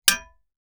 Metal_93.wav